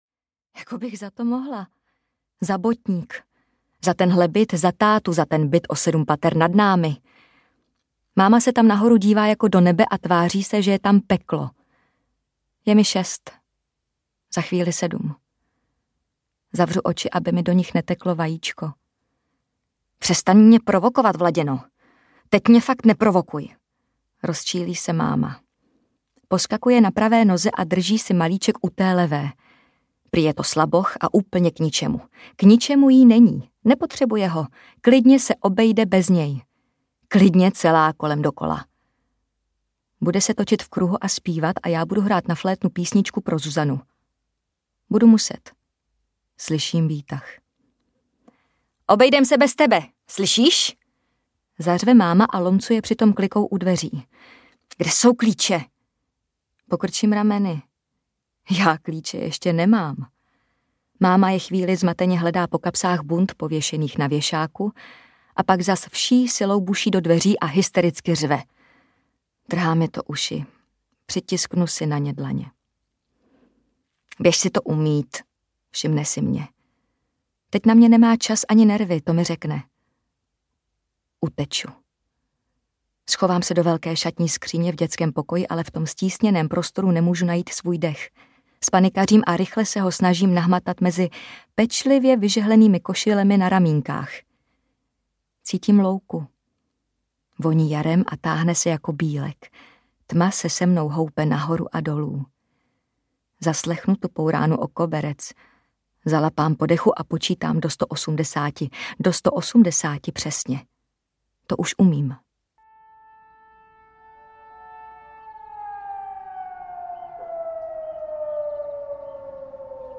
Foukneš do pěny audiokniha
Ukázka z knihy
• InterpretJana Plodková